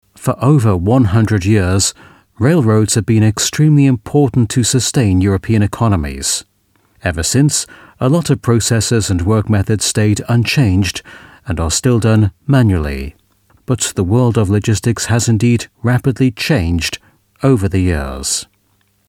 Demos in Audioproduktionen
E-Learning - Logistics